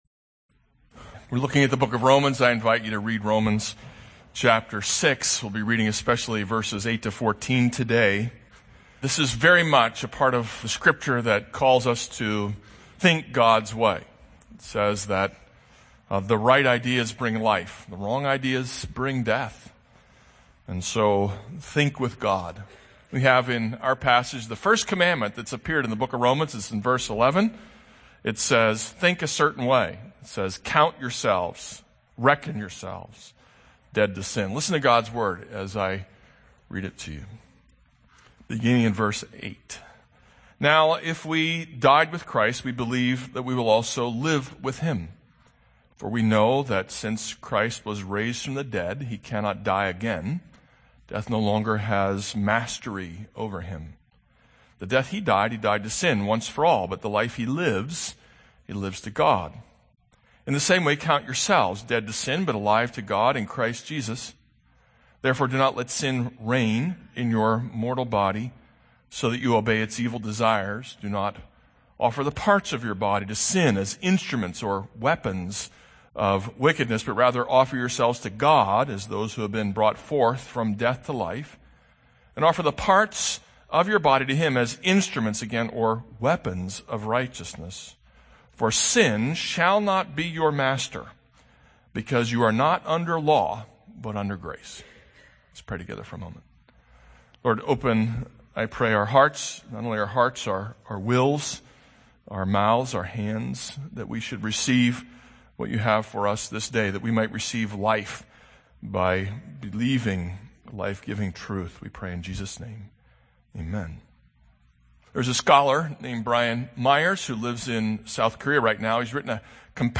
This is a sermon on Romans 6:5-14.